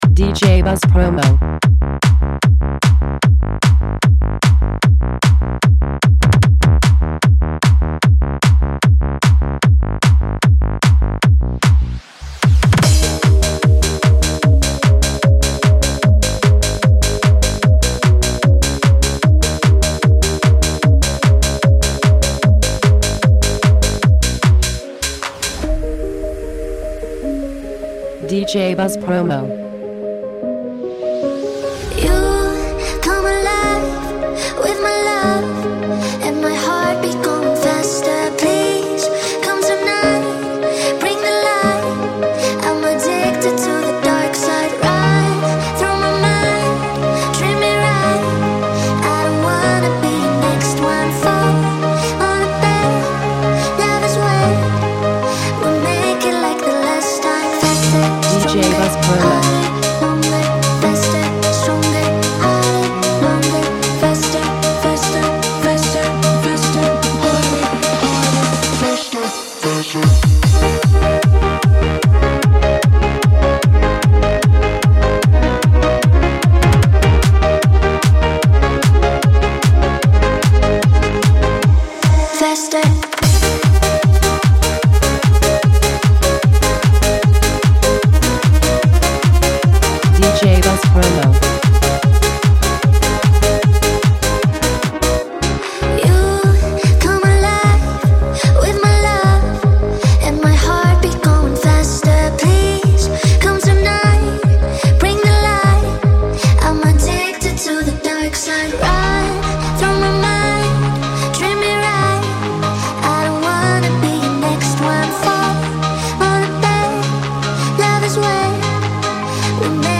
English singer